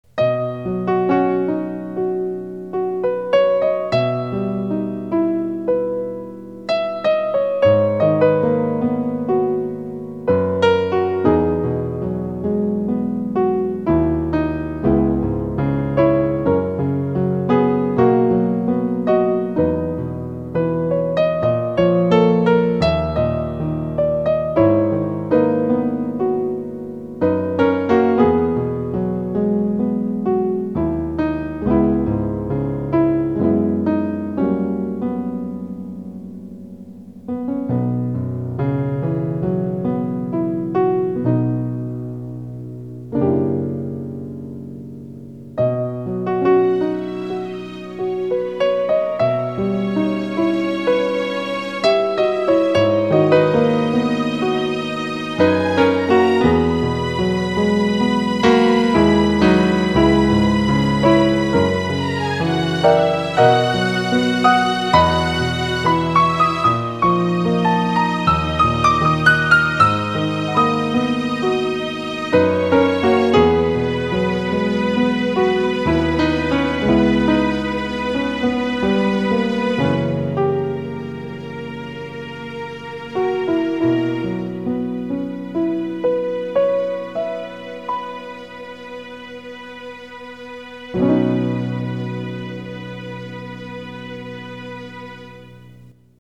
音楽素材として一般受けをねらったいわゆるキレイで単純な感じの曲を作っておくか、と思い
コード進行も単純だし、特に工夫もないコテコテな感じのメロディになるはずだったのですが
途中で若干遊び心が出てしまい、一部オシャレ（？）な感じになっているため